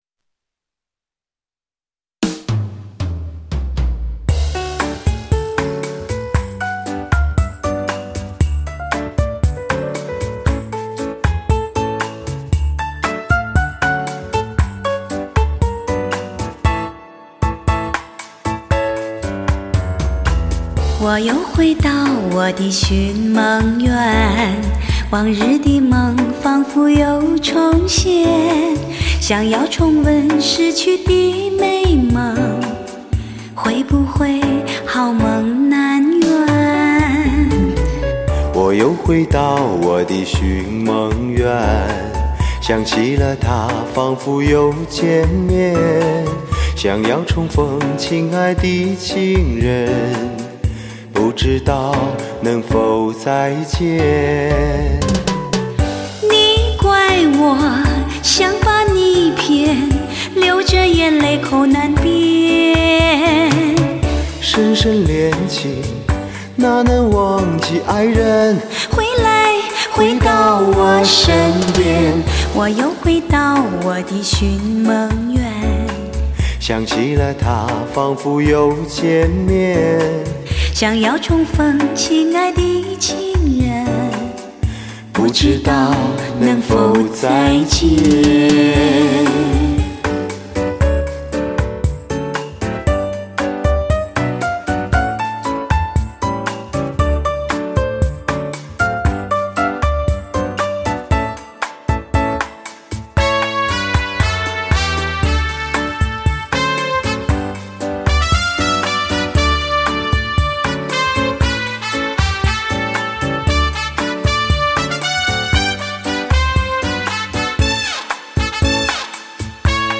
专业汽车音响测试 高保真Hi-Fi 原声大碟
最具温婉魅力女声发烧天碟代表，甜美温暖的人声，柔顺悦耳，如沐春风，优美小号，爵士风味十足的萨克斯，酒廊民乐风情尽显其中。